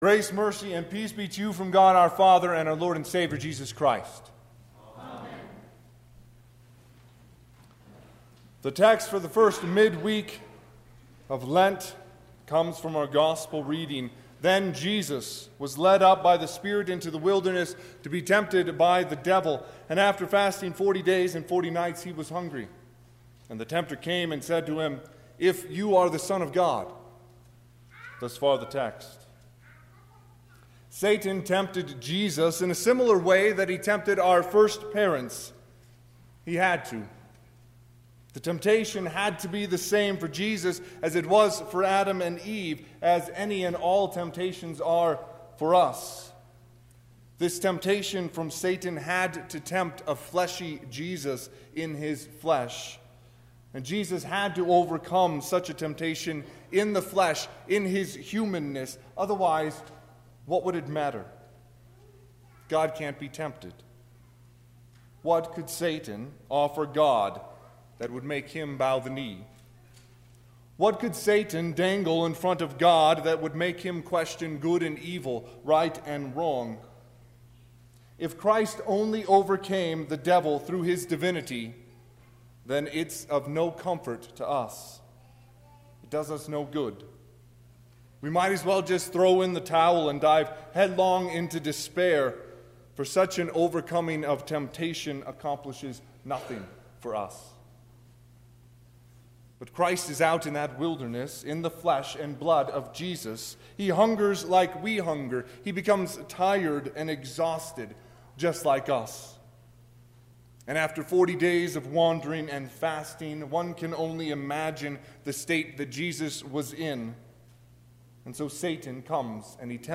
Sermon – 3/4/2020